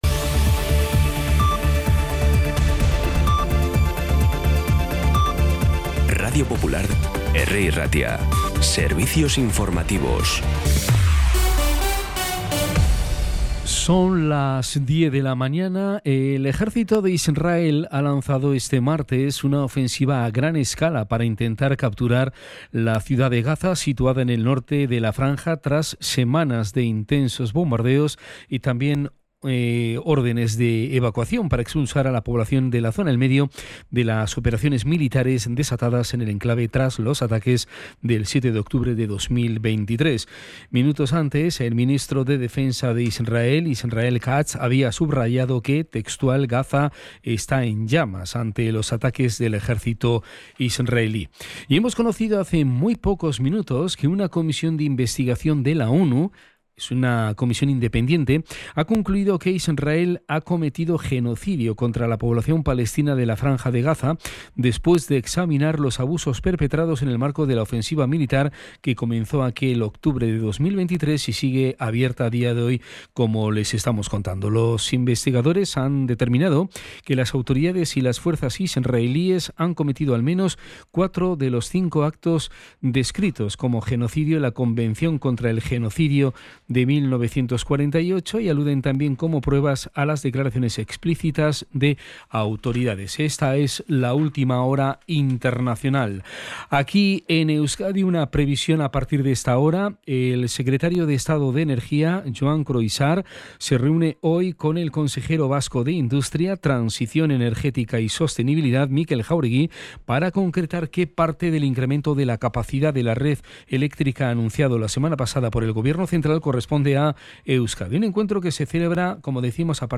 Podcast Informativos
Los titulares actualizados con las voces del día.